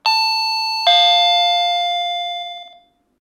Llamada de un timbre de una casa
timbre
Sonidos: Hogar